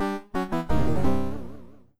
GameOver3.wav